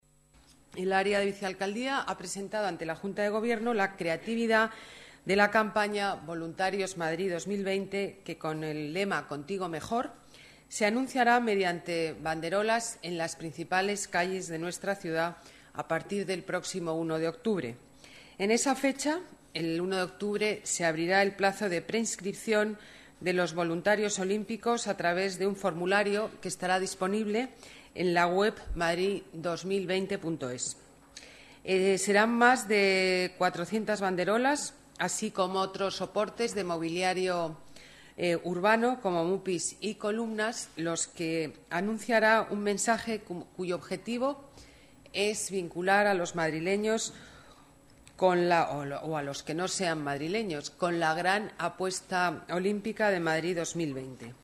Nueva ventana:Declaraciones alcaldesa de Madrid, Ana Botella: campaña Voluntarios Madrid 2020